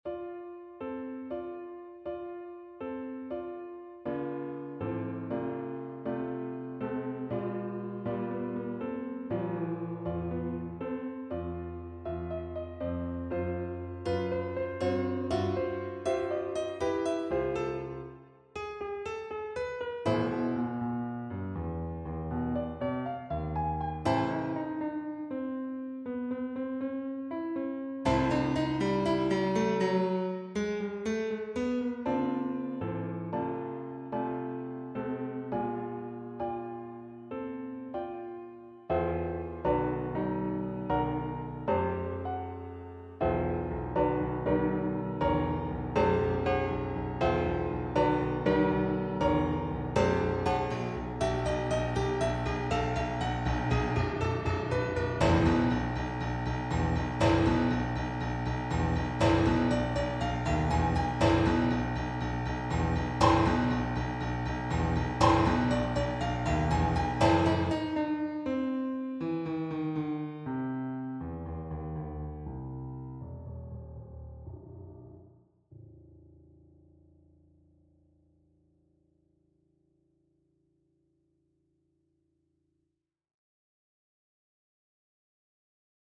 mikrotonalprelud.mp3
Geçen hafta Ivan Wyschnegradsky usülündeki çeyrek-ton sistemiyle yazdığım prelüd.